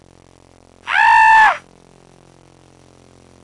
Scream Sound Effect
Download a high-quality scream sound effect.
scream-8.mp3